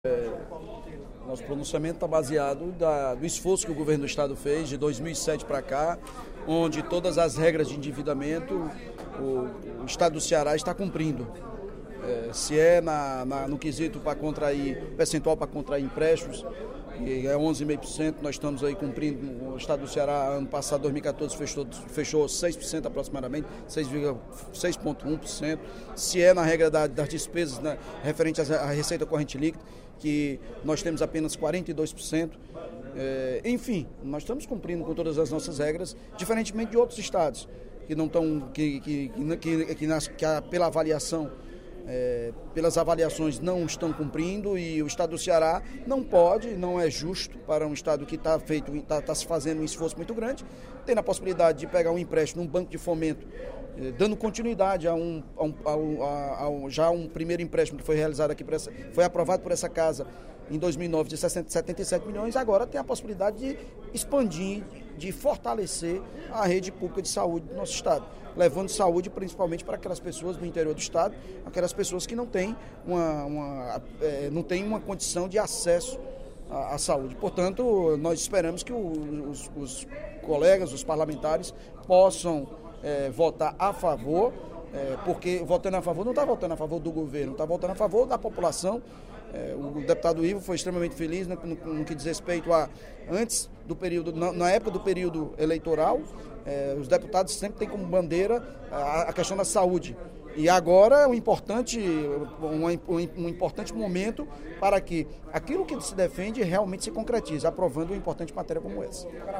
O líder do Governo na Assembleia Legislativa, deputado Evandro Leitão (PDT), em pronunciamento no primeiro expediente da sessão plenária desta quinta-feira (22/10), pediu apoio para a aprovação da mensagem que autoriza o Executivo a adquirir financiamento junto ao Banco Interamericano de Desenvolvimento (BID) para investimentos na saúde.